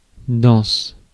Ääntäminen
Ääntäminen France: IPA: [dɑ̃s] Haettu sana löytyi näillä lähdekielillä: ranska Käännös Ääninäyte Adjektiivit 1. thick US 2. dense US 3. condensed US 4. concentrated US Suku: f .